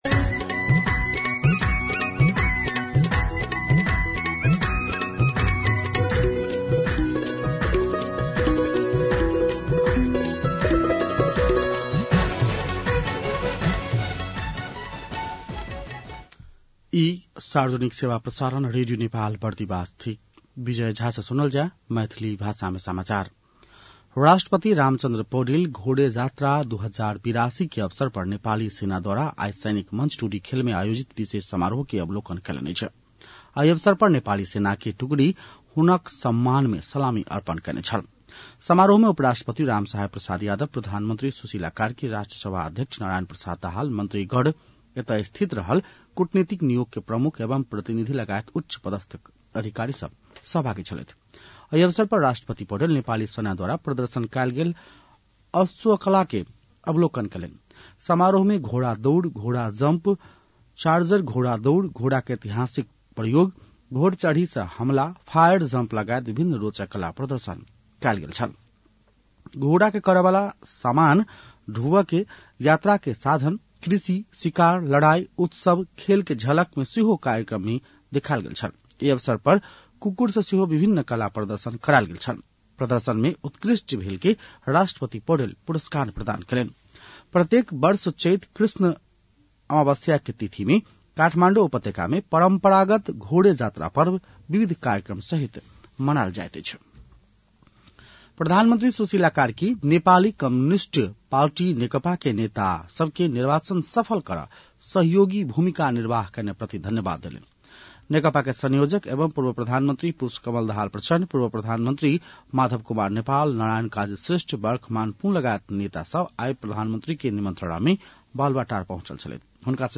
मैथिली भाषामा समाचार : ४ चैत , २०८२